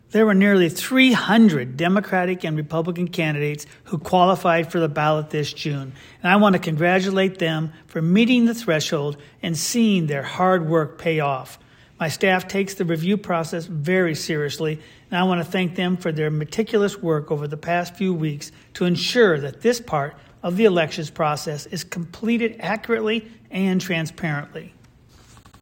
A soundbyte from Secretary Pate is available for your use at this link.